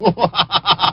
Ghost Wizpig's laugh sound with real pinch.